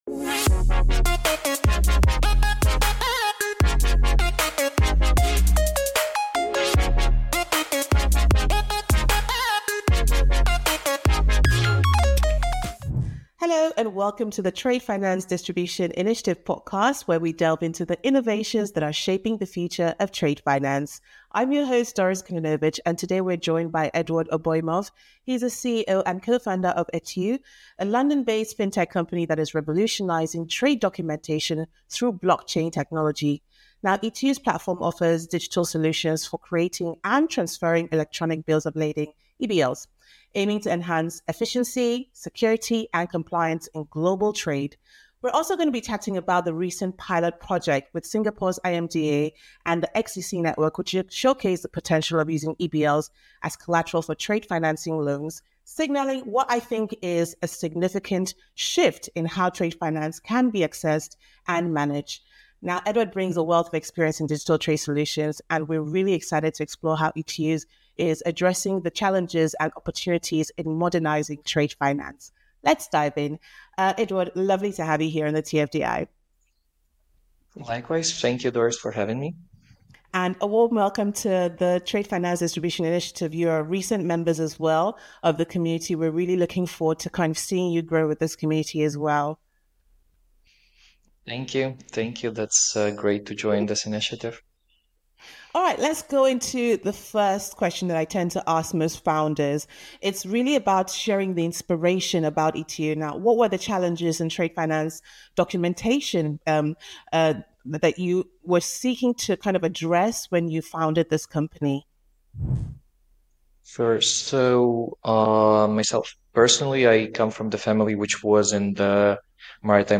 This conversation offers a window into how eTEU is driving access, efficiency, and trust in a $30 trillion trade ecosystem.